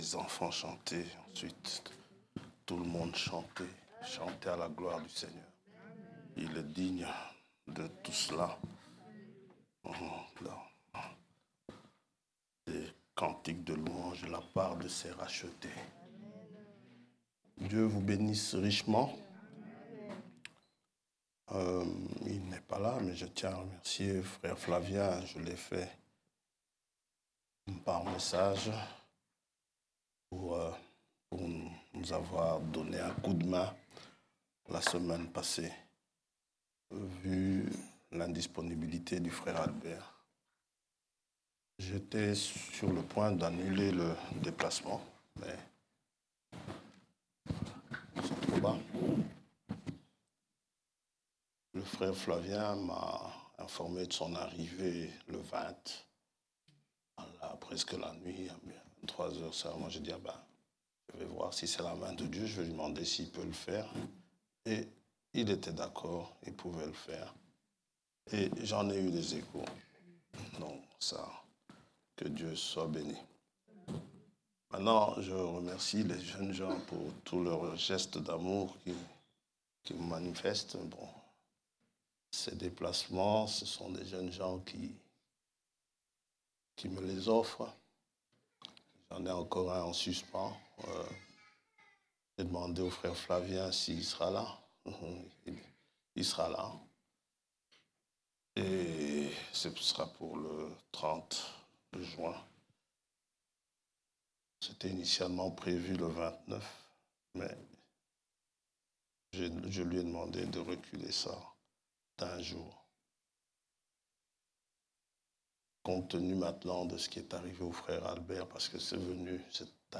Prédications